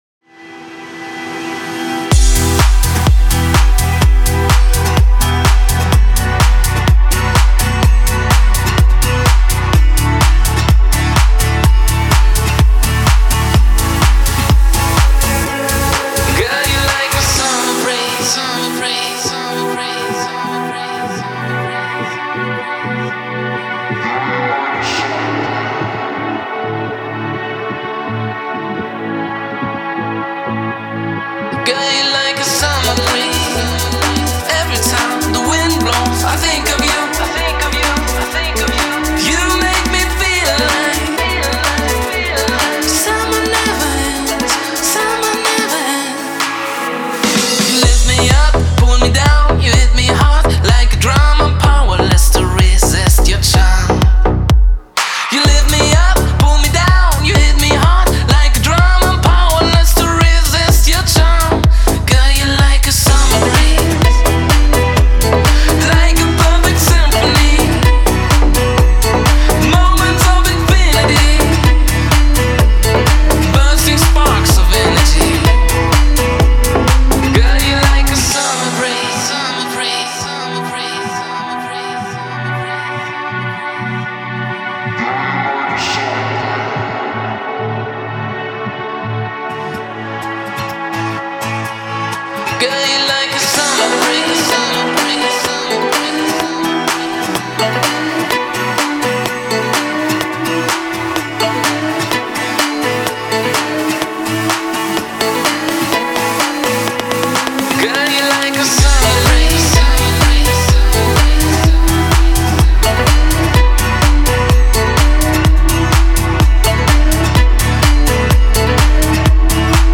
это трек в жанре электронного попа